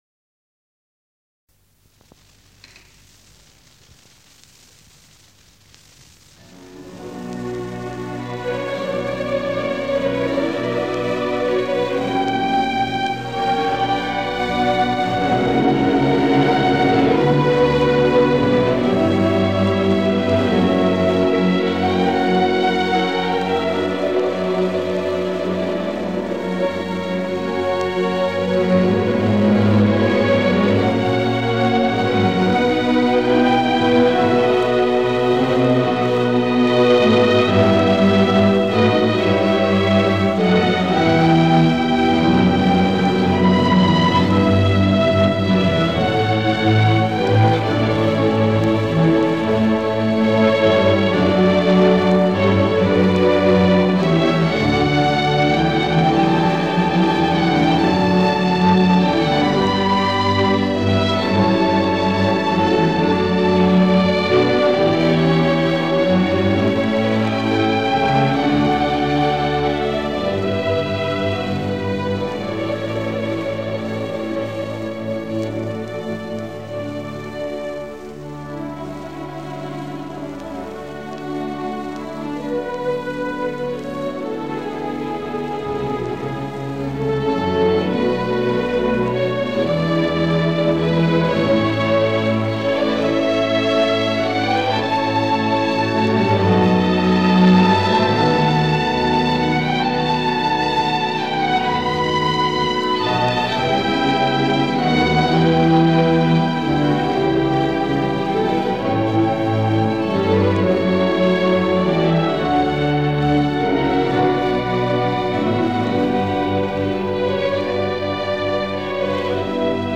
Bach, Johann Sebastian – Orchestral Suite no. 2 Concertgebouw Orchestra Amsterdam Maandag, 17 april, 1939 ; Live recording 01 - Ouverture, 02 - Rondeau, 03 - Bourrée I & II, 04 - Sarabande, 05 - Polonaise I & II, 06 - Minuetto, 07 - Badinerie